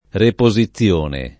reposizione → riposizione
vai all'elenco alfabetico delle voci ingrandisci il carattere 100% rimpicciolisci il carattere stampa invia tramite posta elettronica codividi su Facebook reposizione [ repo @ i ZZL1 ne ] → riposizione